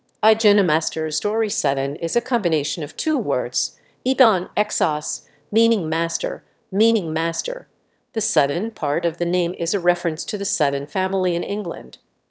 story1_CopyMachine_1.wav